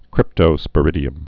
(krĭptō-spə-rĭdē-əm)